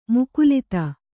♪ mukulita